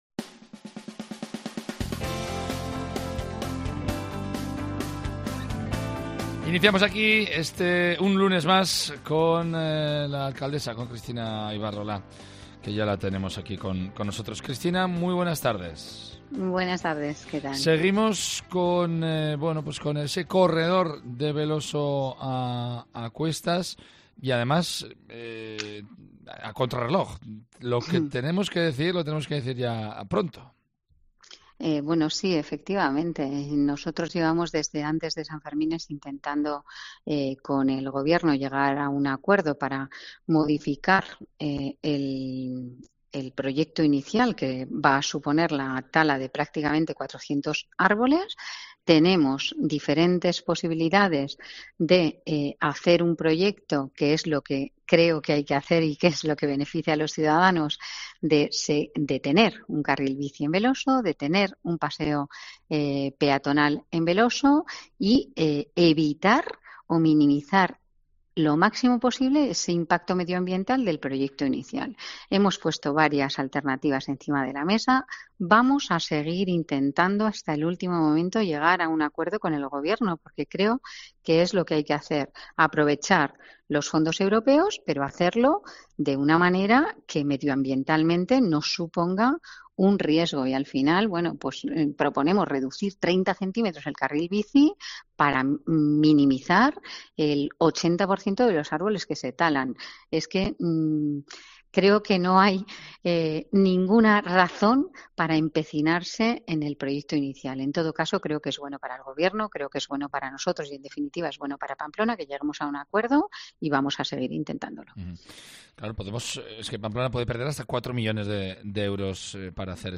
AUDIO: La alcaldesa Cristina Ibarrola responde las preguntas en Cope Navarra. Polémica por el corredor de Beloso, atropello mortal en la calle...